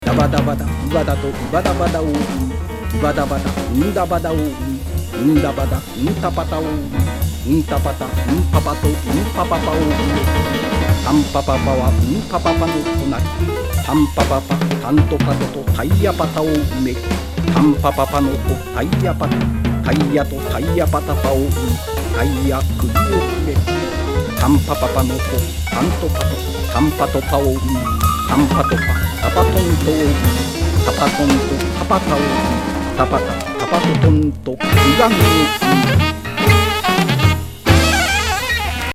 旧約聖書とジャズのスキャットを混ぜ合わせた？